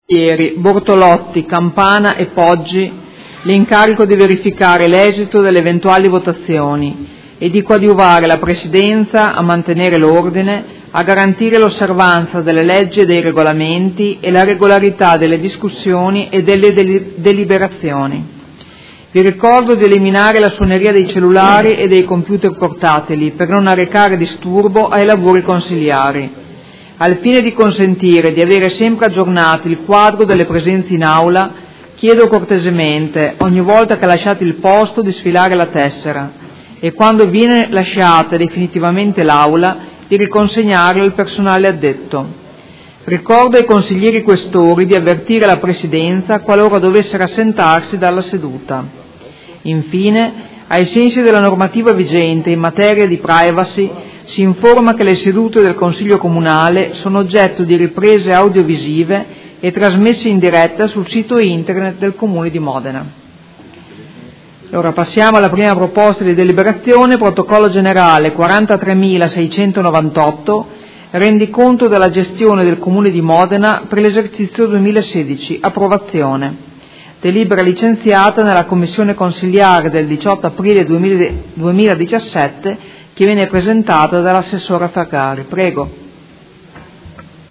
Presidentessa — Sito Audio Consiglio Comunale
Seduta del 27/04/2017 Apre i lavori del Consiglio Comunale.